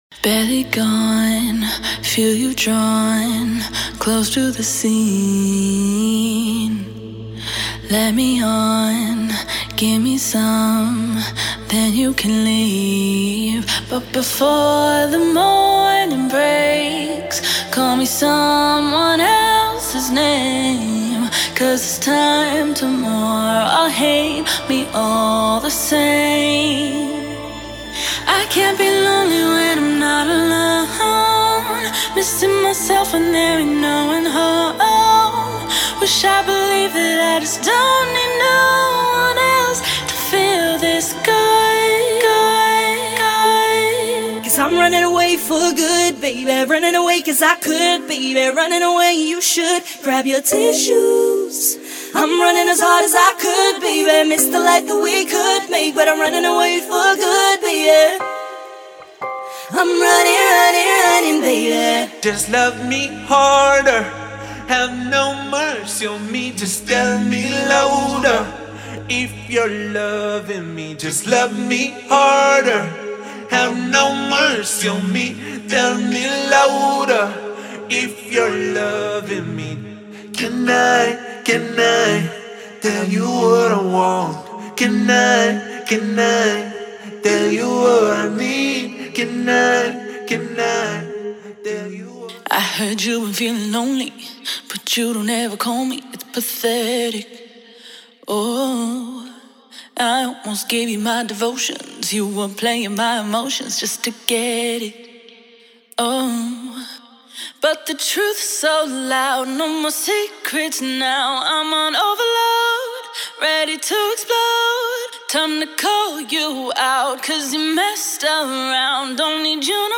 • Wet & Dry stems